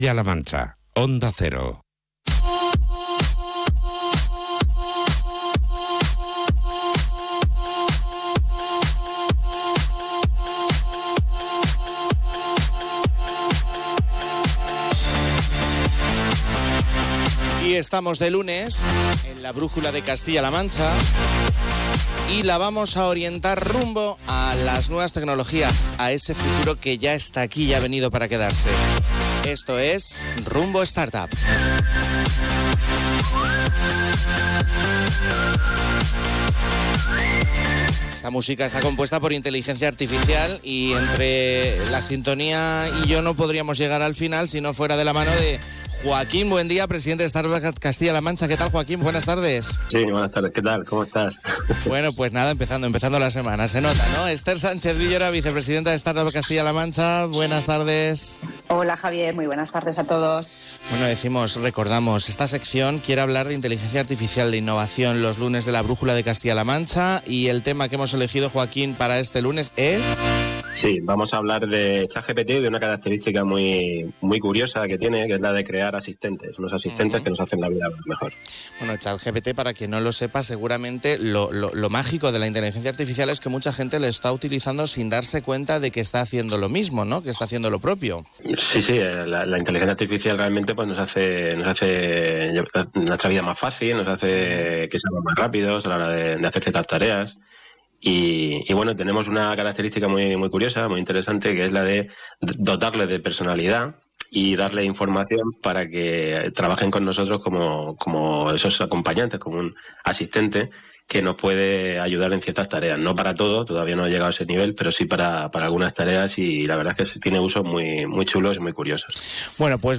🎙 En la reciente entrega de ‘Rumbo Startup’, escuchamos una charla fascinante sobre cómo la inteligencia artificial, y en especial chat GPT, nos está cambiando la vida de maneras sorprendentes.